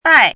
chinese-voice - 汉字语音库
bai4.mp3